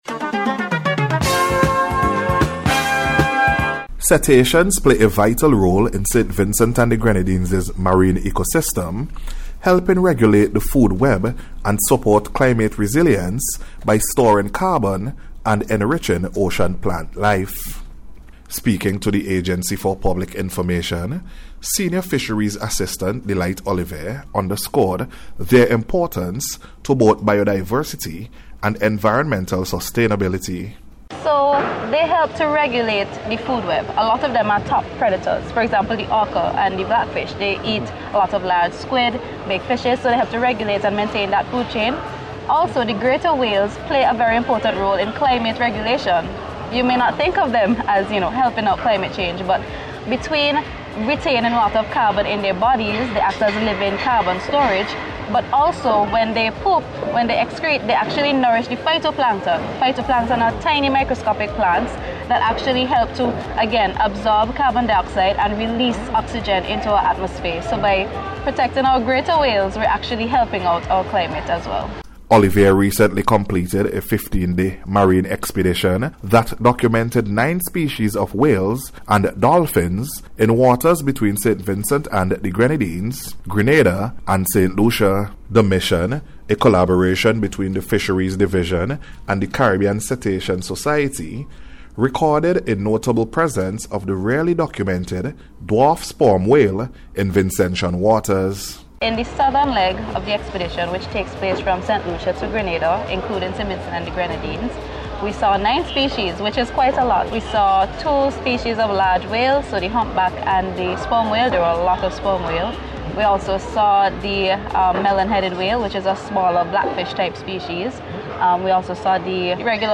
MARINE-LIFE-REPORT.mp3